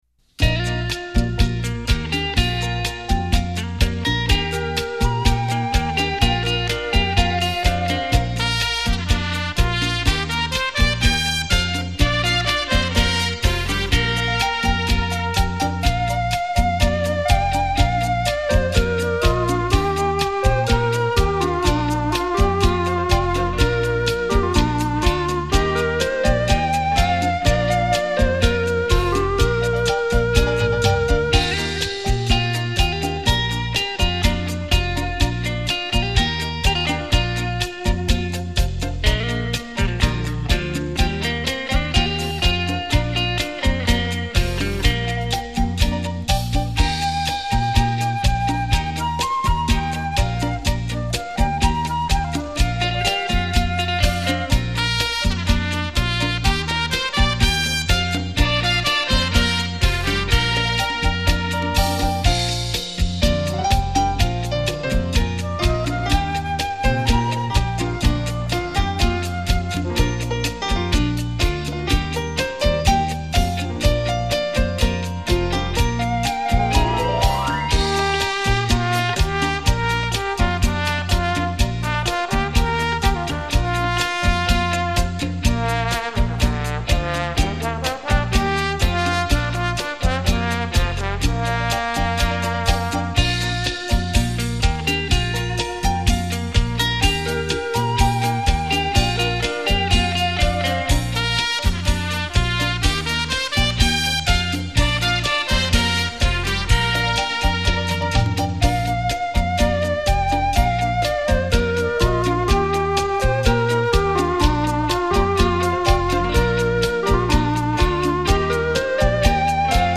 繞場立體音效 發燒音樂重炫
電聲演繹發燒珍品·值得您精心收藏·細細聆賞...